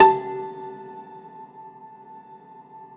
53o-pno13-A2.wav